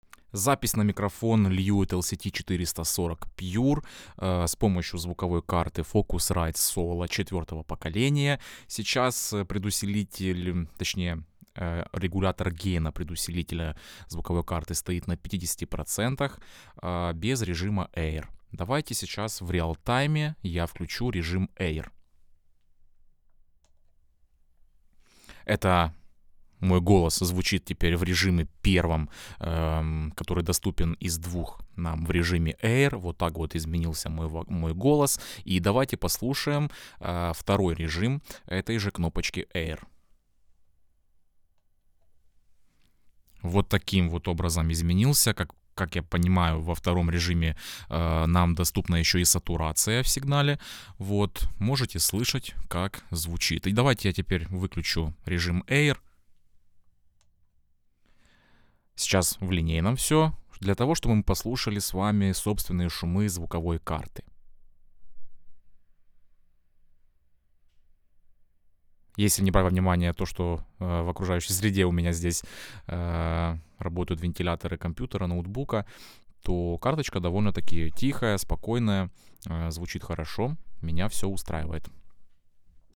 focusrite-scarlett-solo-4th-gen-golos-ru.mp3